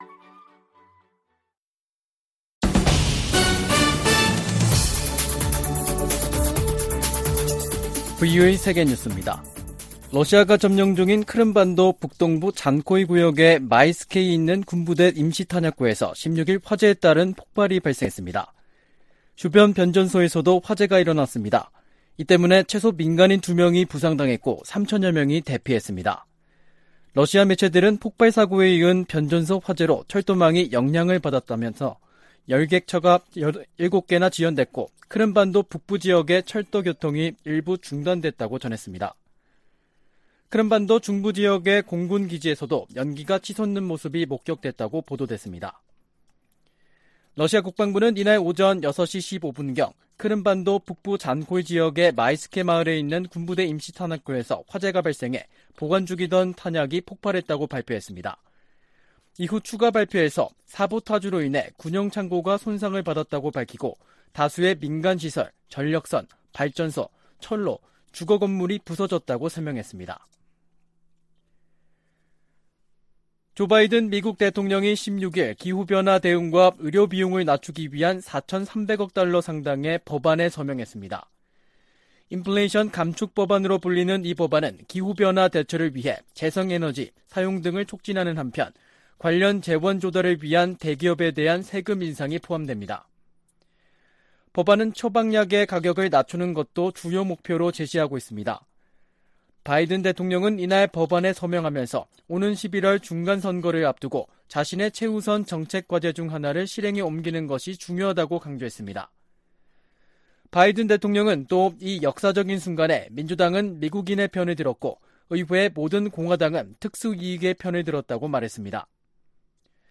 VOA 한국어 간판 뉴스 프로그램 '뉴스 투데이', 2022년 8월 17일 3부 방송입니다. 북한이 두 달여 만에 미사일 발사 도발을 재개했습니다. 윤석열 한국 대통령은 광복절 경축사에서 밝힌 담대한 구상 대북 제안은 북한이 비핵화 의지만 보여주면 적극 돕겠다는 것이라며 북한의 호응을 촉구했습니다. 미 국무부는 미국과 한국의 연합 군사훈련이 한국 안보를 지키기 위한 순수한 방어 목적임을 강조했습니다.